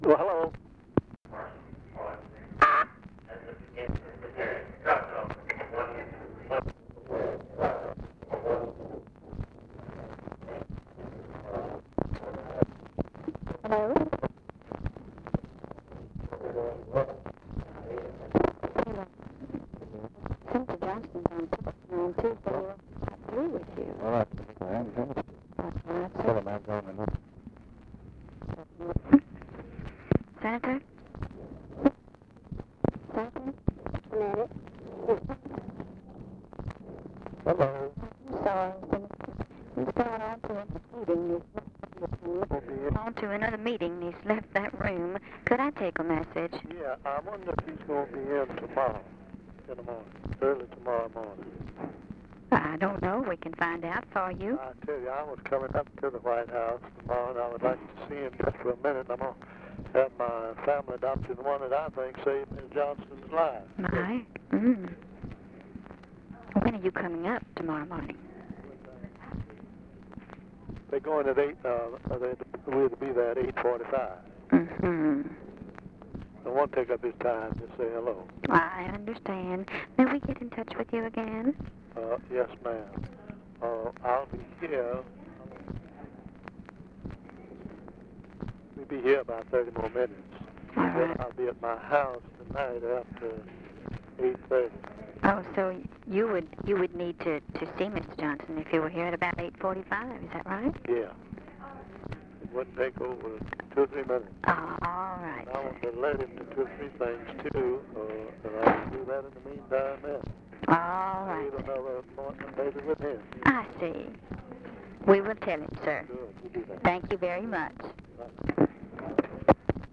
Conversation with OLIN JOHNSTON, December 5, 1963
Secret White House Tapes